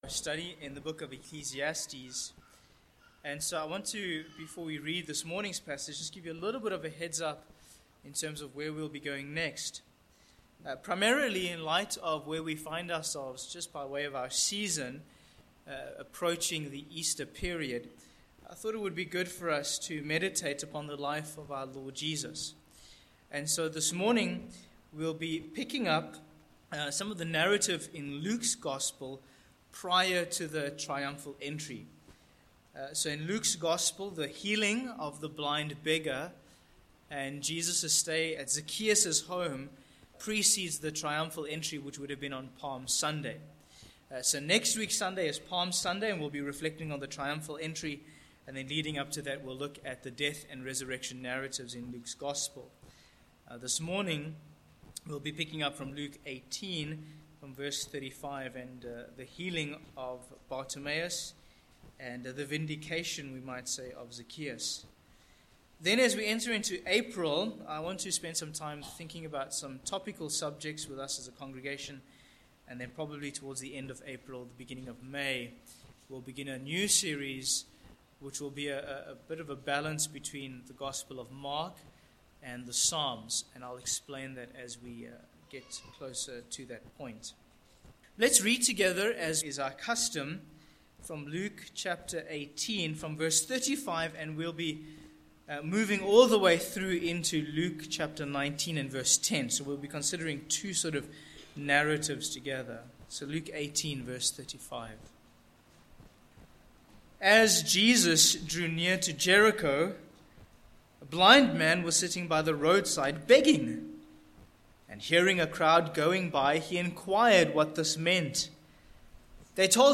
Sermon points: 1. Blind Man Bartimaeus 2. Rich Man Zacchaeus